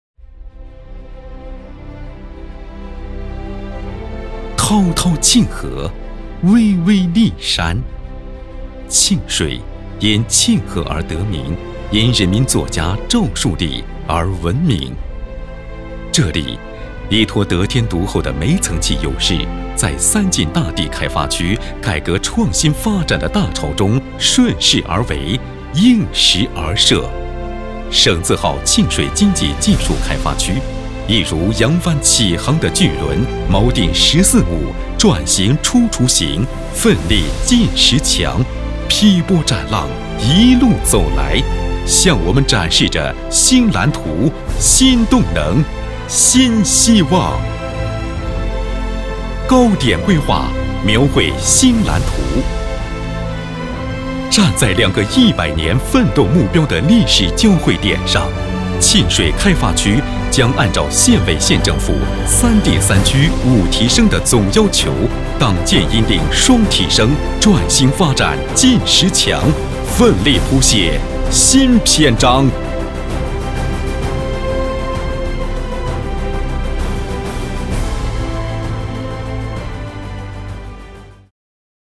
淘声配音网，专题，宣传片配音，专业网络配音平台 - 淘声配音网配音师男国语203号 大气 沉稳 厚重
配音风格： 大气 沉稳 厚重